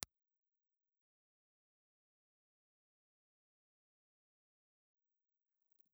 Impulse Response file of Aiwa VM-15 ribbon microphone.
Aiwa_VM15_IR.wav